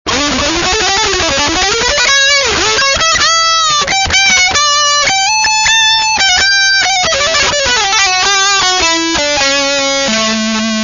lead guitar.